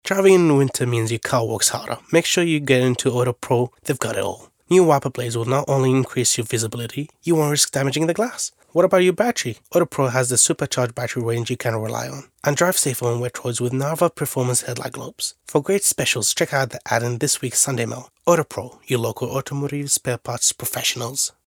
Arabic Voiceover Download Audio